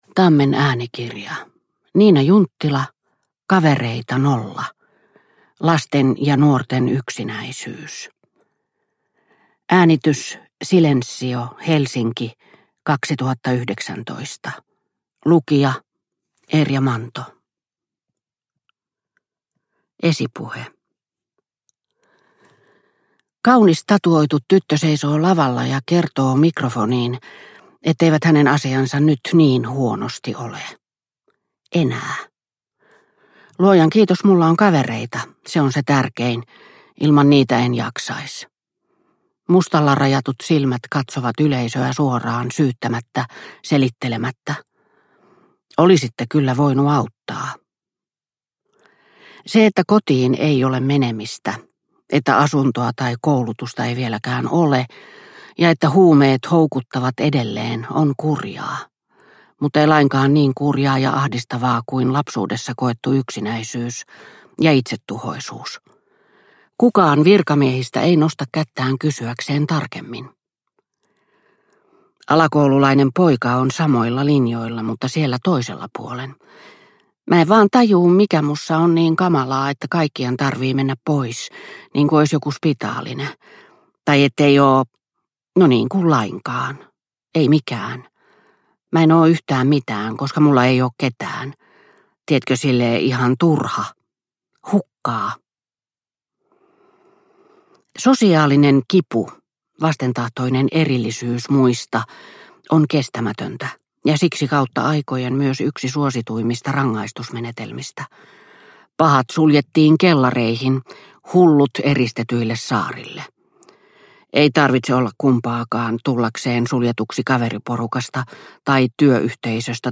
Kavereita nolla (ljudbok) av Niina Junttila